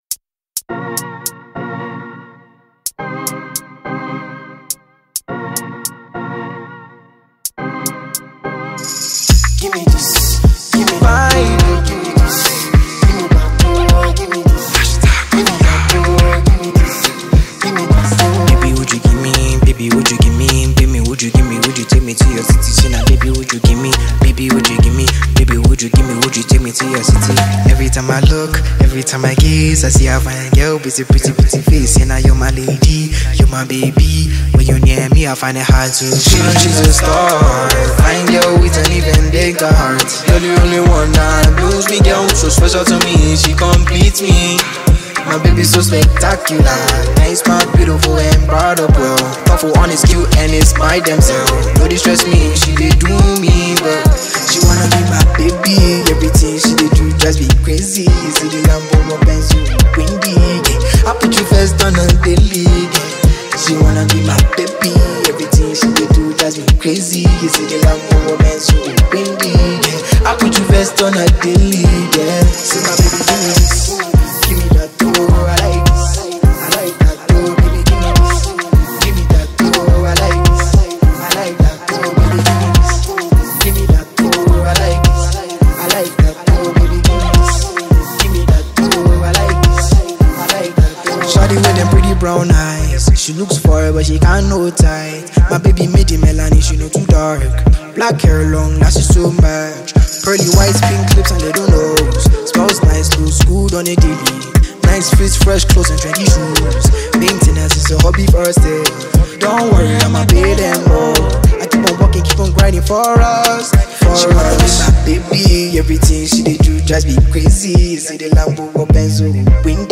Afrobeat Music